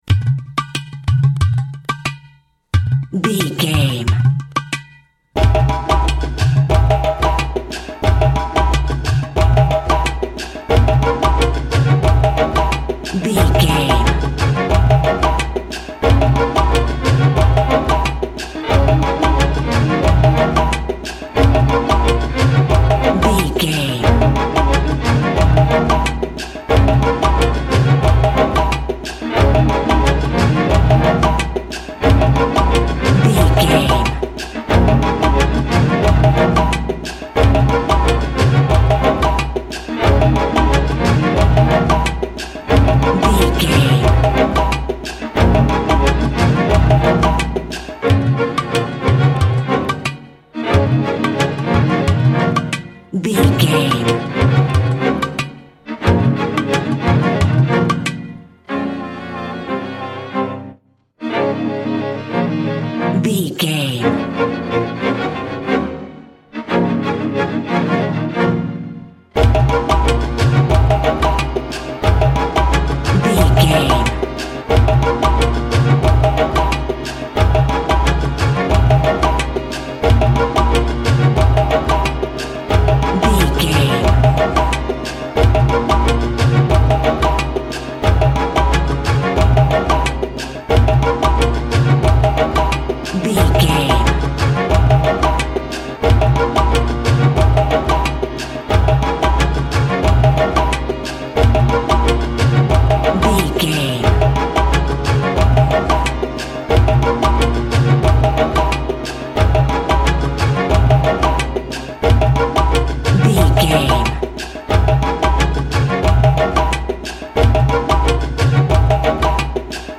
Aeolian/Minor
B♭
world beat
drums
percussion